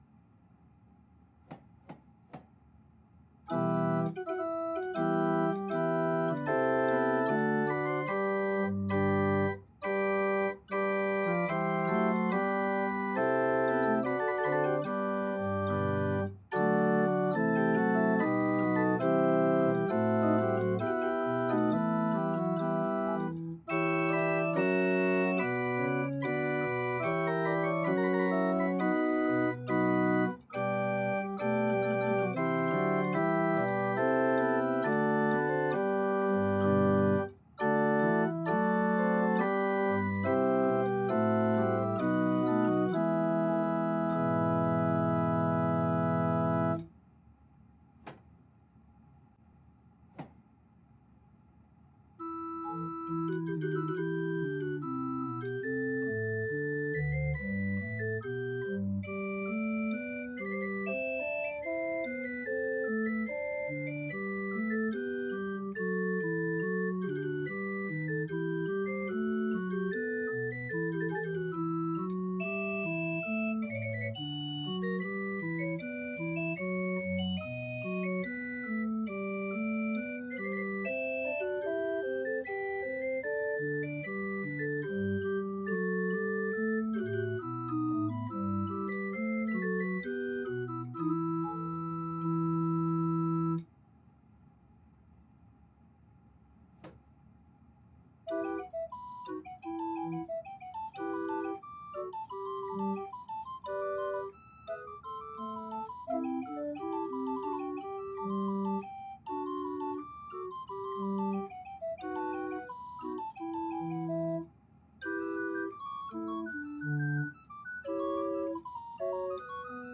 The recording was done in a small, dry acoustic.